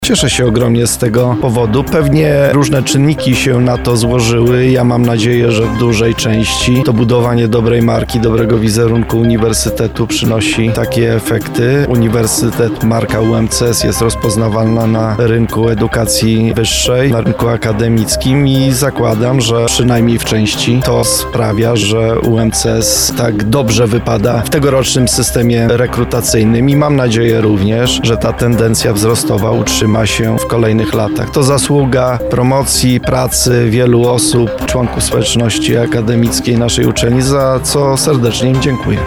prof. Radosław Dobrowolski – mówi prof. dr hab. Radosław Dobrowolski, Rektor UMCS.